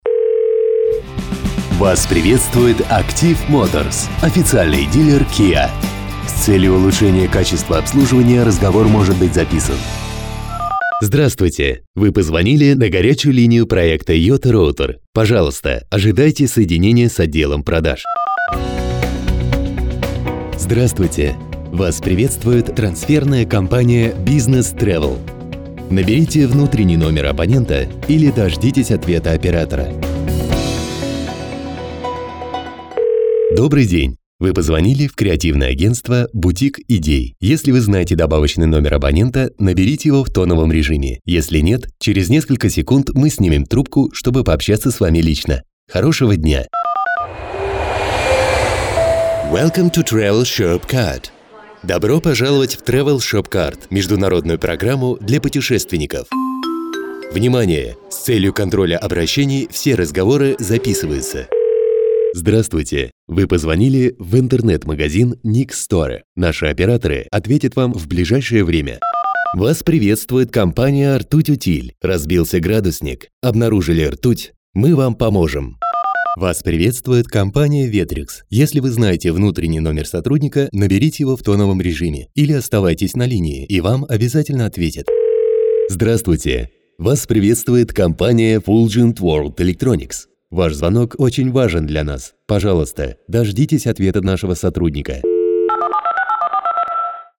ДЕМО записи автоответчиков и IVR Категория: Аудио/видео монтаж